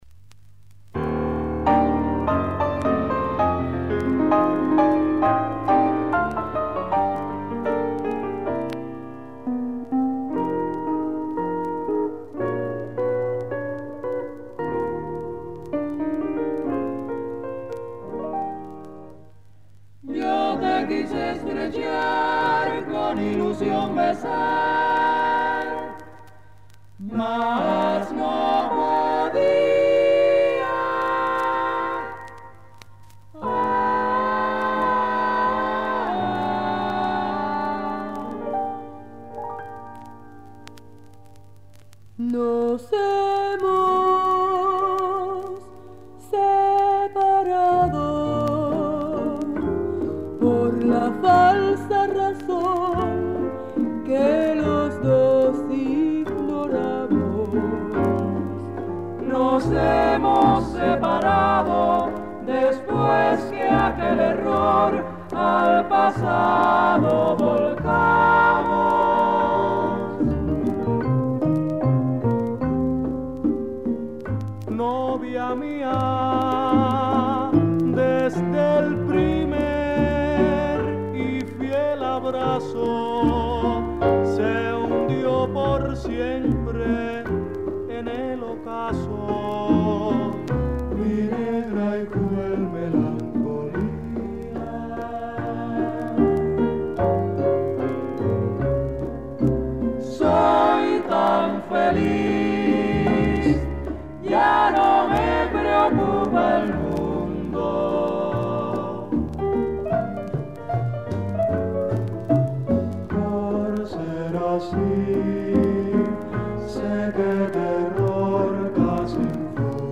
男女混成ボーカルグループ
５０年代から活動している、キューバの男女混成ボーカルグループ
ダンサブルなモザンビーケから